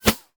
bullet_flyby_fast_11.wav